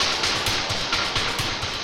RI_DelayStack_130-02.wav